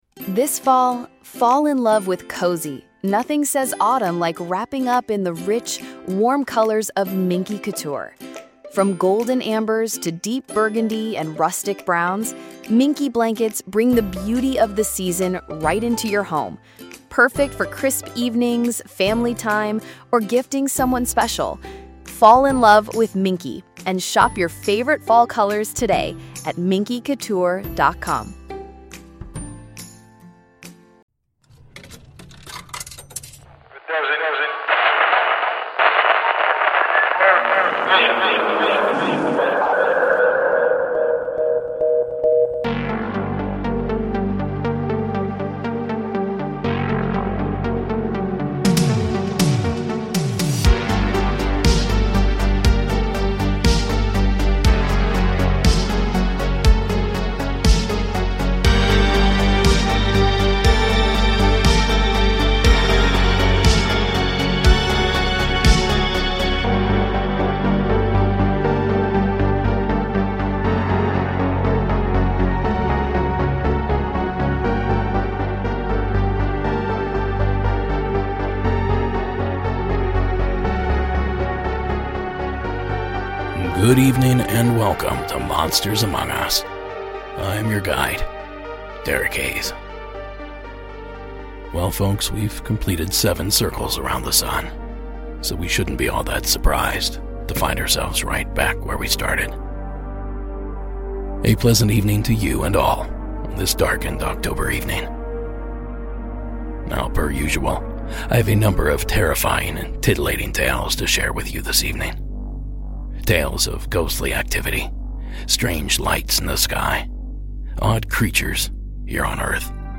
Season 14 Episode 5 of Monsters Among Us Podcast, true paranormal stories of ghosts, cryptids, UFOs and more told by the witnesses themselves.